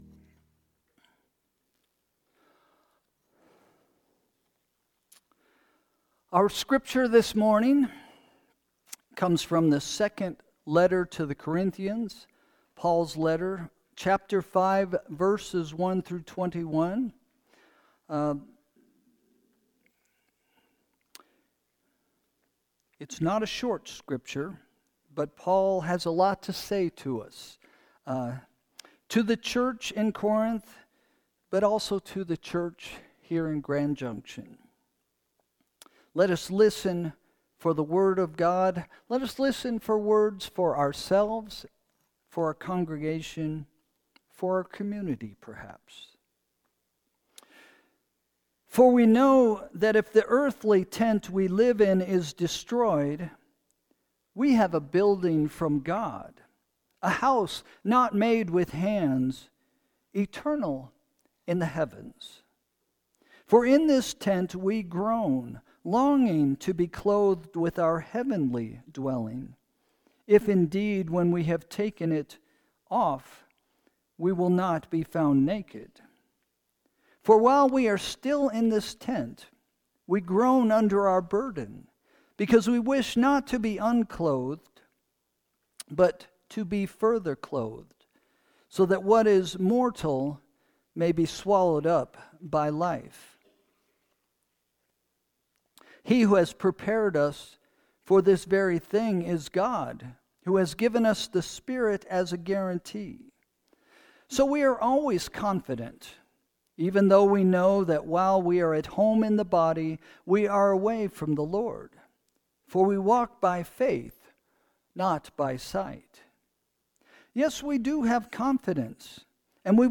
Sermon – August 24, 2025 – “Look in the Mirror”